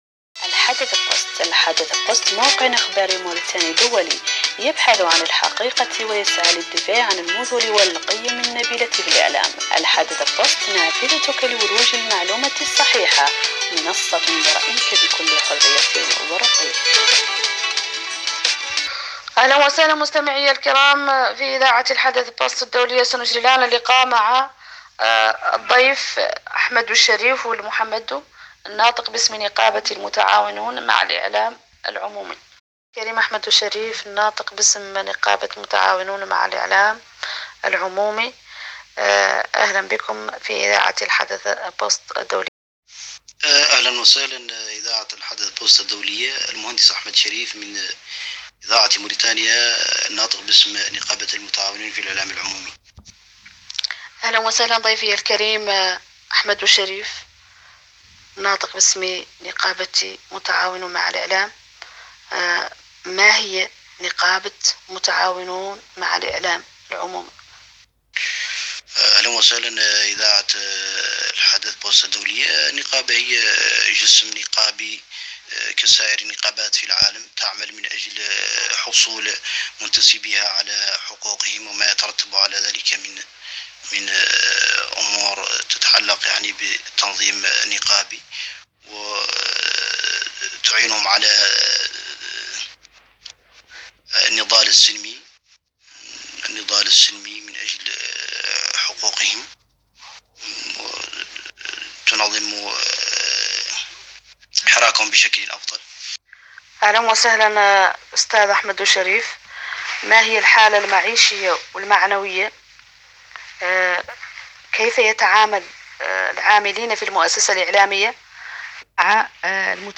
المقابلة في الملف الصوتي التالي